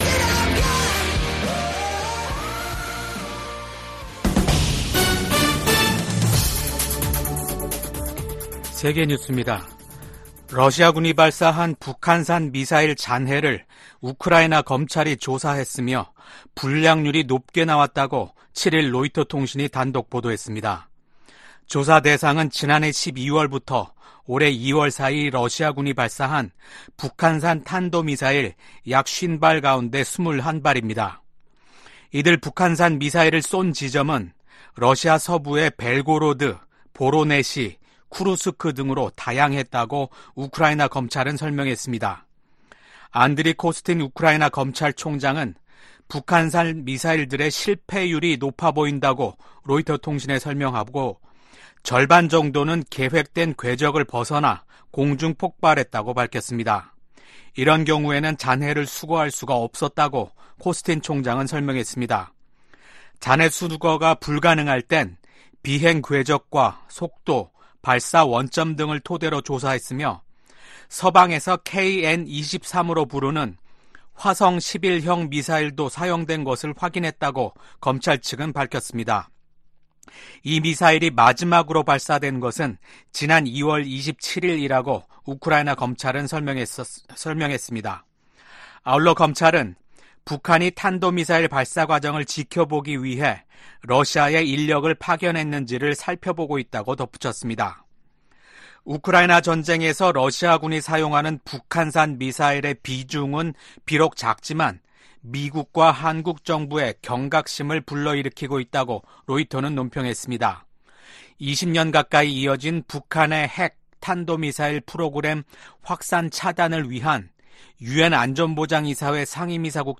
VOA 한국어 아침 뉴스 프로그램 '워싱턴 뉴스 광장' 2024년 5월 8일 방송입니다. 백악관이 유엔이 정한 연간 한도를 초과한 대북 정제유 공급과 관련해 제재를 이행하지 않고 있는 나라가 있다고 비판했습니다. 국방부가 6일 북한의 로켓 엔진시험 정황과 관련해 한국과 일본에 대한 굳건한 방위 공약을 재확인했습니다. 미국과 한국 일본, 유럽국가들이 러시아의 유엔 안보리 거부권 남용을 비판했습니다.